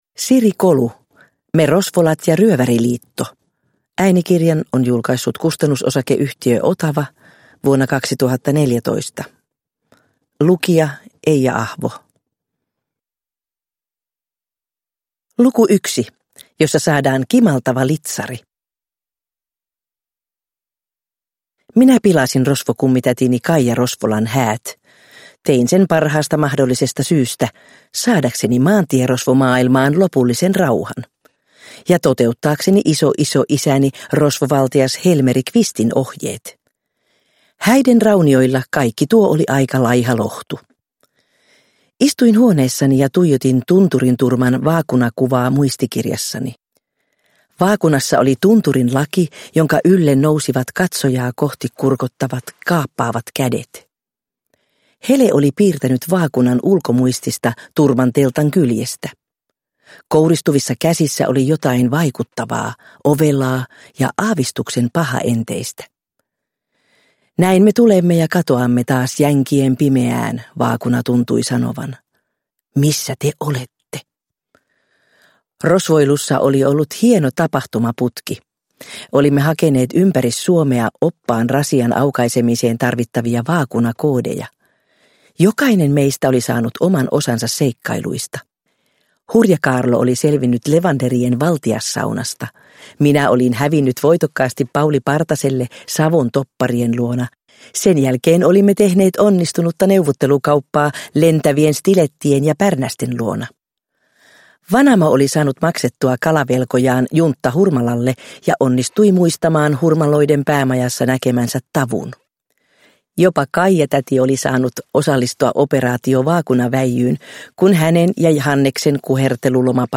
Me Rosvolat ja ryöväriliitto – Ljudbok – Laddas ner
Uppläsare: Eija Ahvo